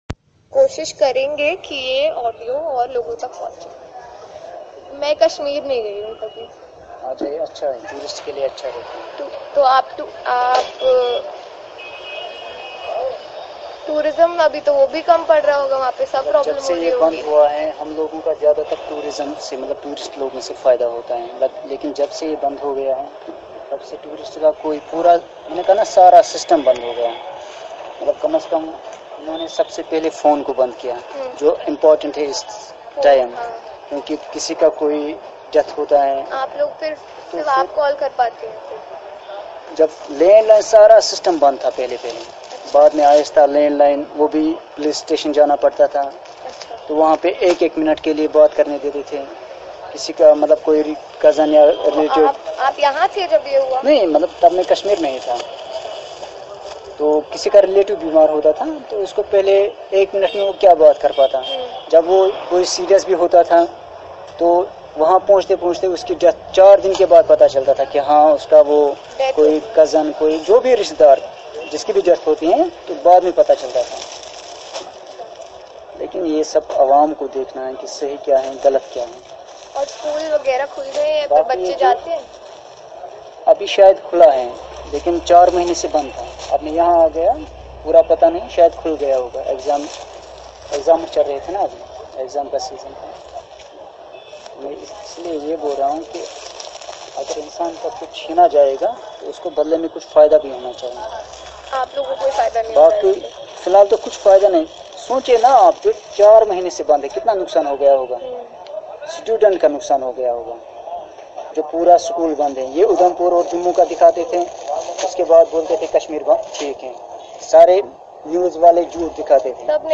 Unwoven Dreams: A Conversation with a Pashmina Salesman — Audio Interview